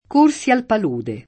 k1rSi al pal2de] (Dante) — tosc. padule, s. m. (ant. come s. f.), spec. in locuz. come caccia di padule, fieno di padule — sim. i top.